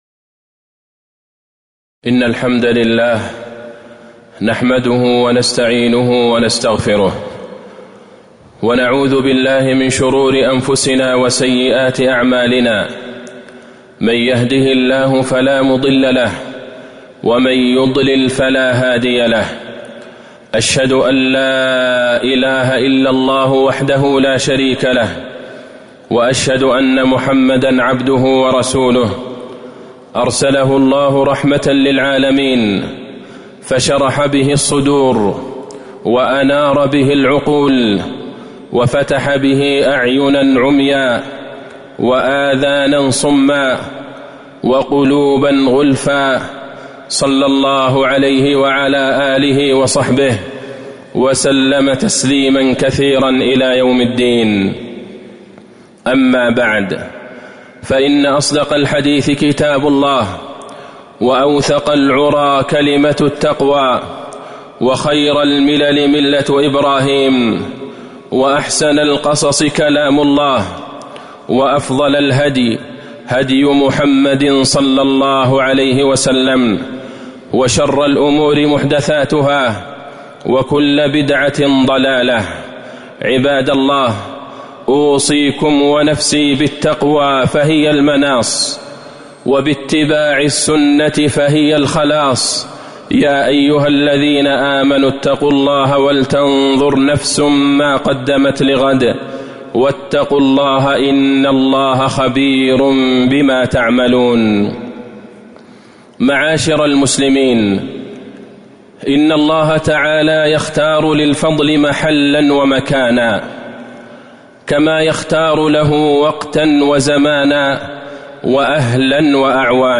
تاريخ النشر ٢٥ ذو القعدة ١٤٤٣ هـ المكان: المسجد النبوي الشيخ: فضيلة الشيخ د. عبدالله بن عبدالرحمن البعيجان فضيلة الشيخ د. عبدالله بن عبدالرحمن البعيجان إرشادات وتوجيهات للحجاج